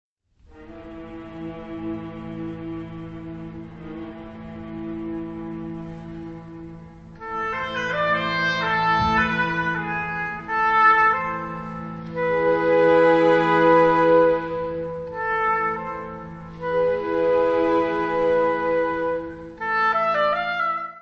orquestra
tenor
: stereo; 12 cm
Music Category/Genre:  Classical Music
Lá m D911.